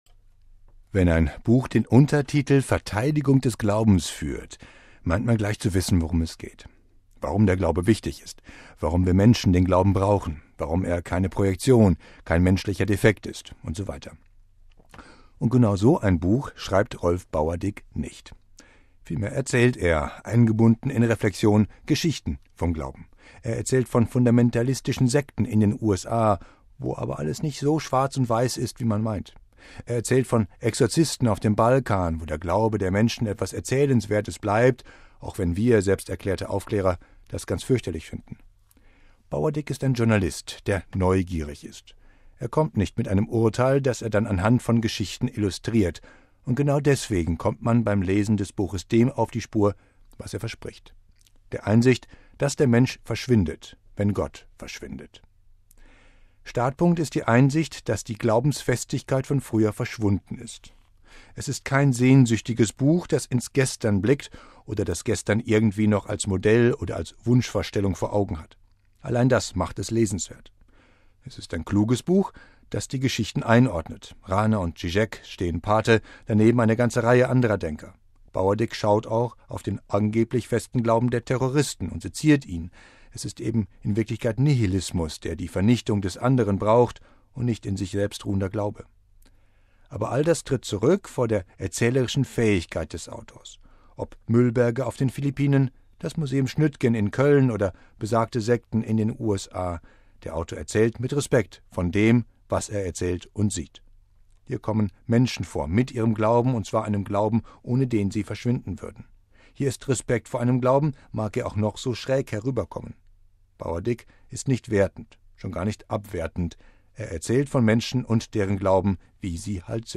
Buchtipp: Wenn Gott verschwindet, verschwindet der Mensch